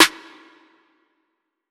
Waka SNARE ROLL PATTERN (49).wav